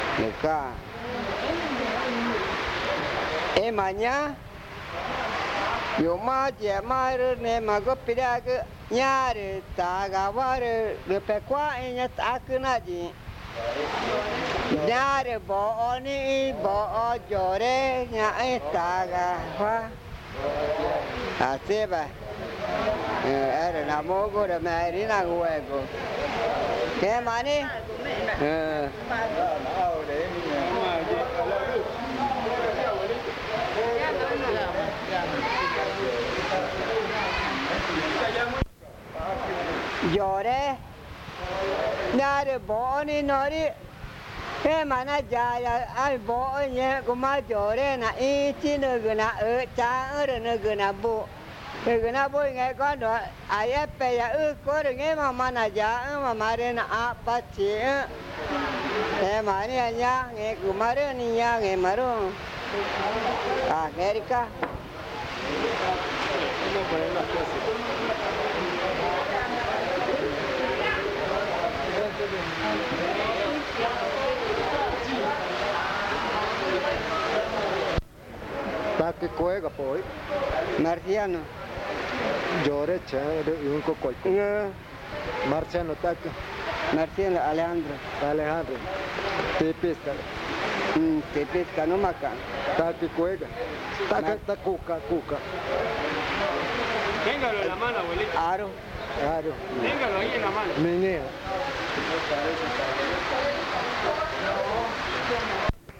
Tipisca, Amazonas (Colombia)